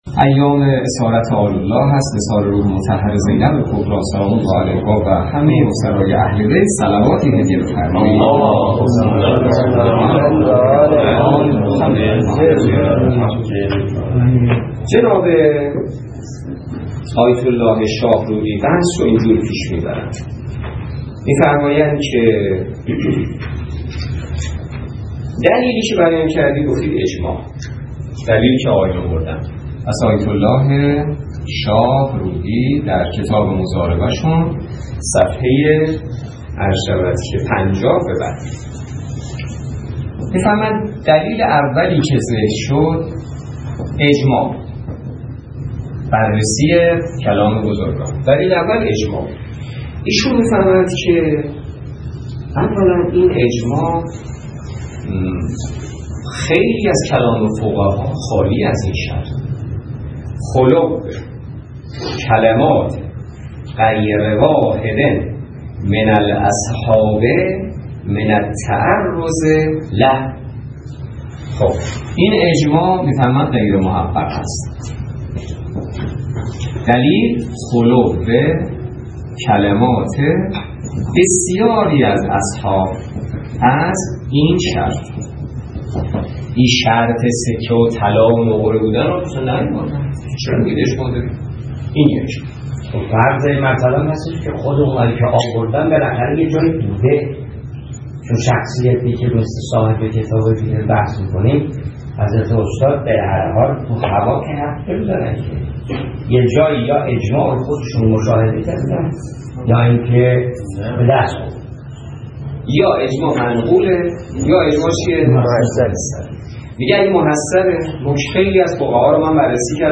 درس فقه